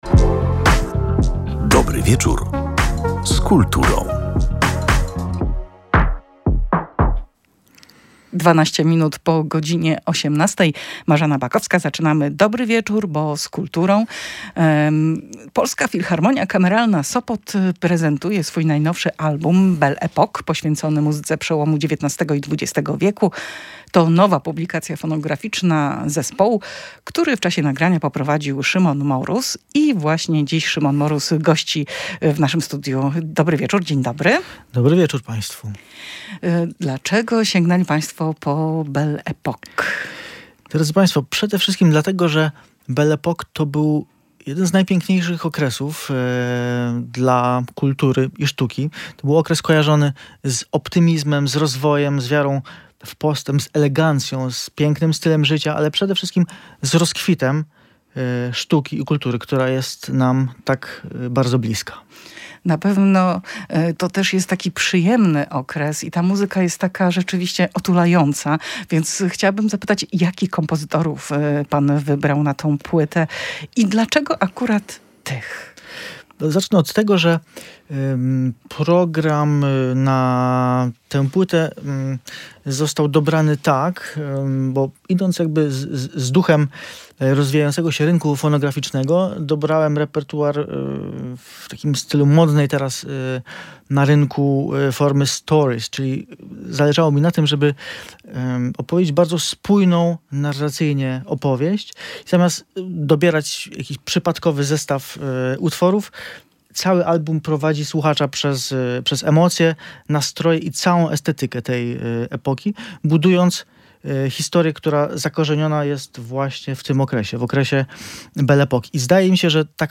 W audycji na żywo